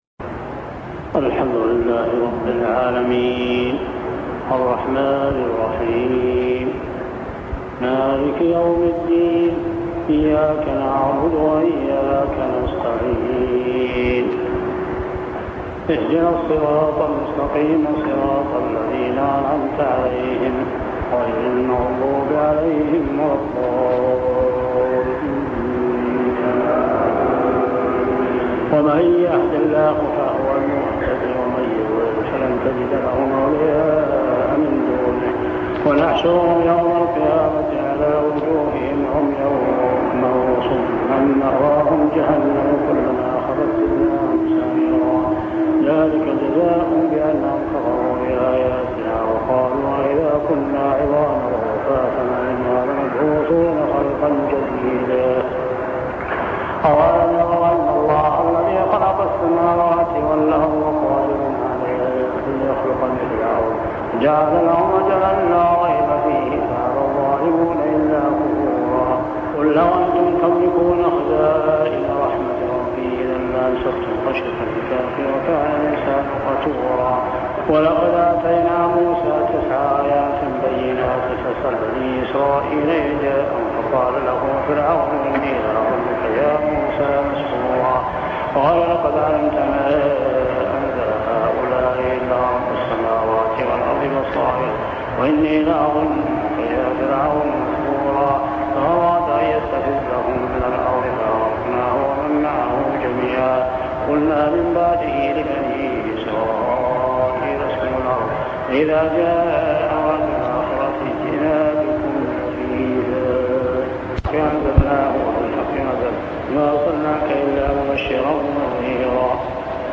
صلاة التراويح عام 1401هـ سورتي الإسراء 97-111 و الكهف 1-59 | Tarawih prayer Surah Al-Isra and Al-Kahf > تراويح الحرم المكي عام 1401 🕋 > التراويح - تلاوات الحرمين